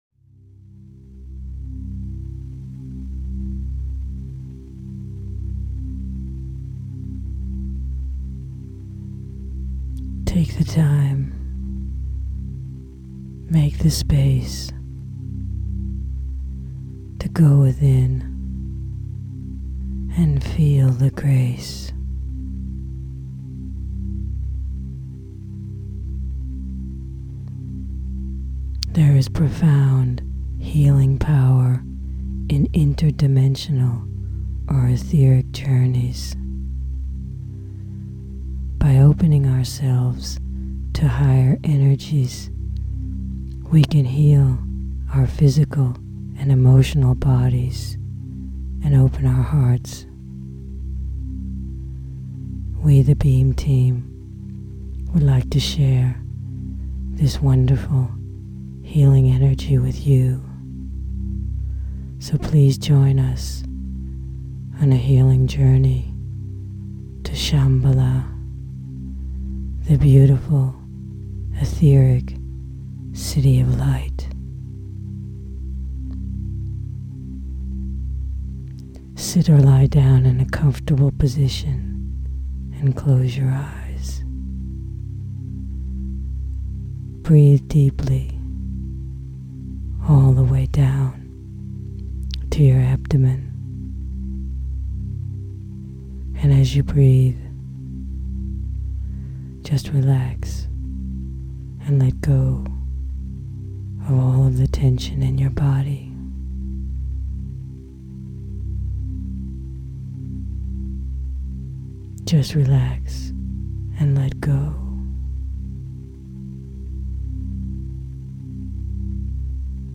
3 Guided Meditations - CD